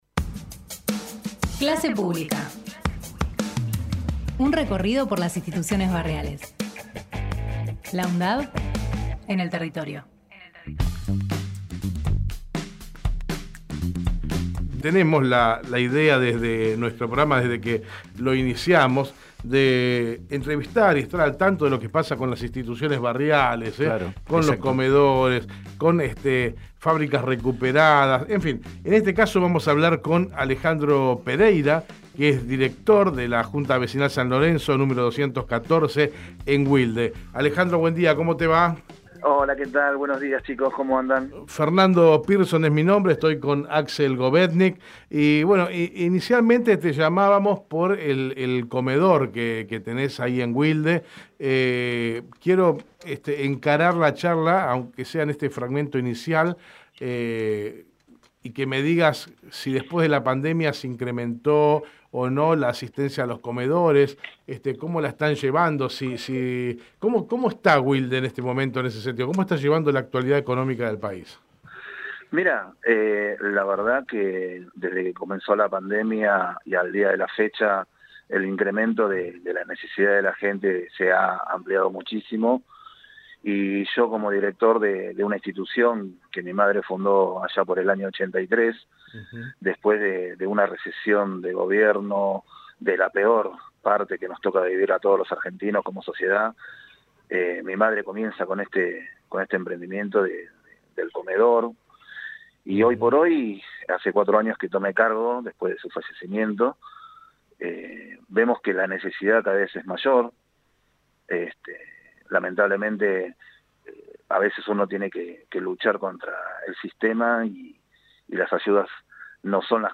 Texto de la nota: Compartimos la entrevista